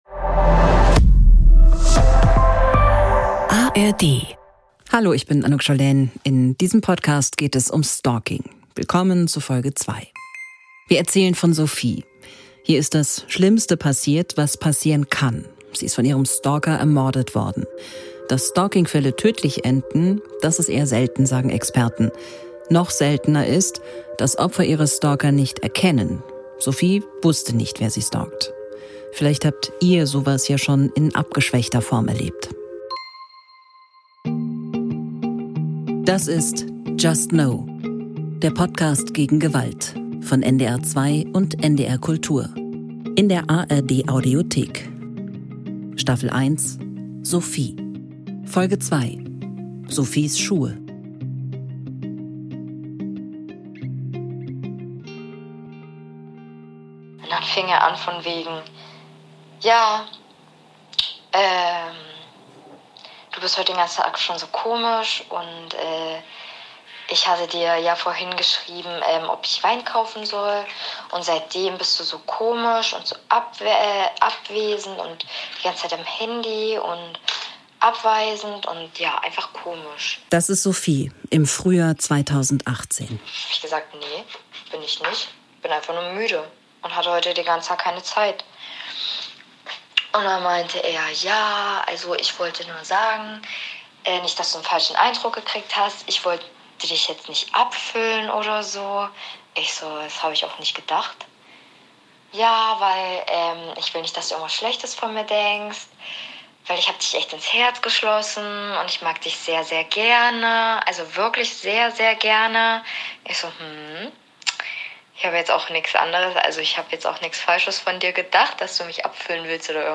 just no! ist ein True Crime-Podcast von NDR 2 und NDR Kultur von 2023.